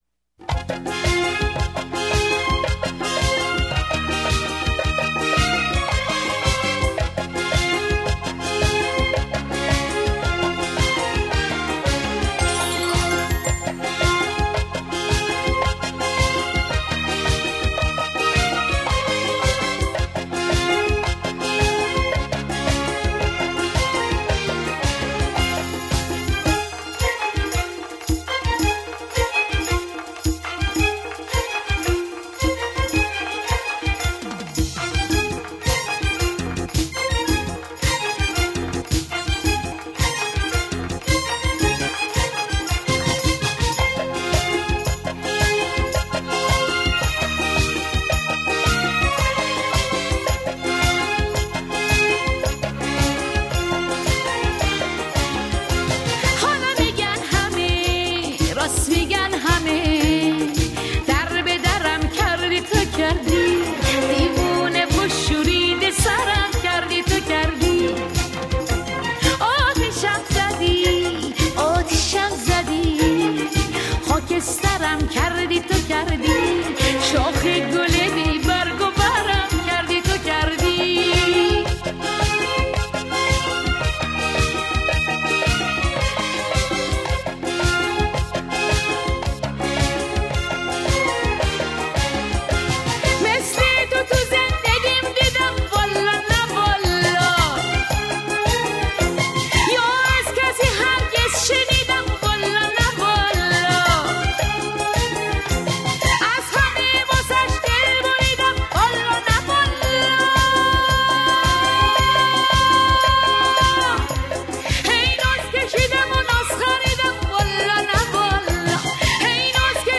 اهنگ شاد ایرانی
اهنگ شاد قدیمی